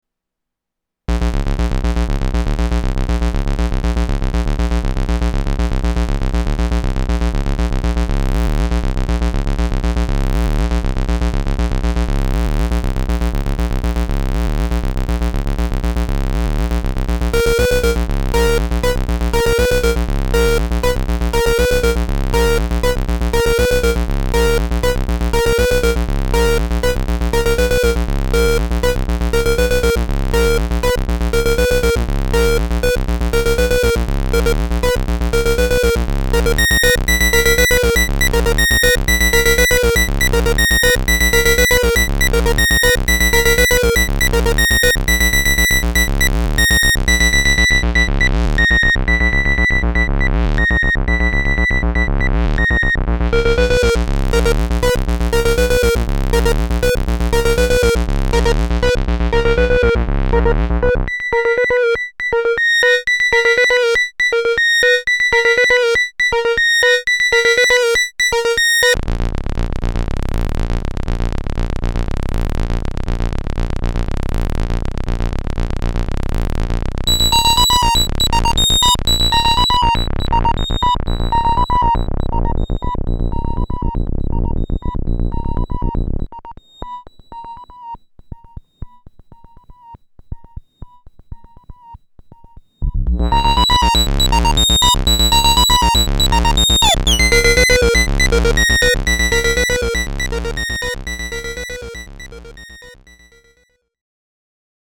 These allow the oscillators to be sequenced either together as one group, individually as three separate parts, or as a pair of two and a separate single oscillator.
All other controls – i.e. the filter, the envelope and the LFO – affect all three oscillators regardless of grouping, but this still means you can build up multi-part patterns with harmony or counter-melodies.
3-parts-on-volca-bass.mp3